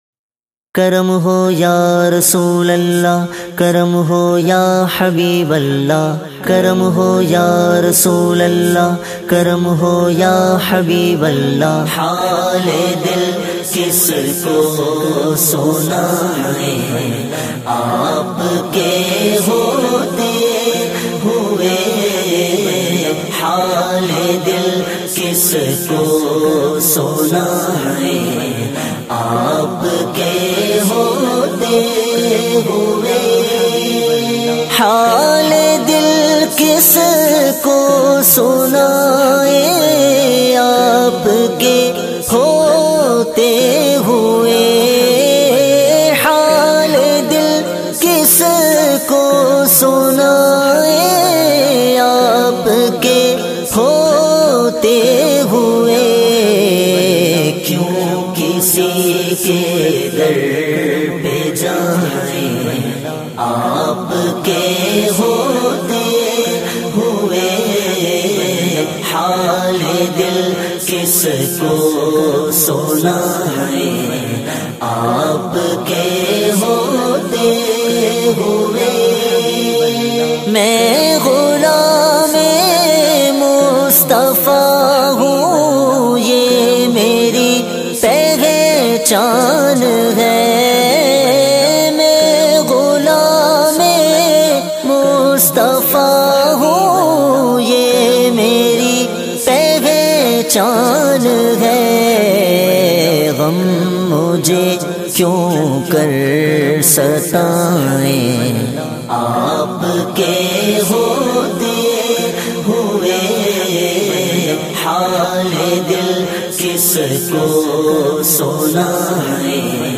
Heart touching Naat
in a Heart-Touching Voice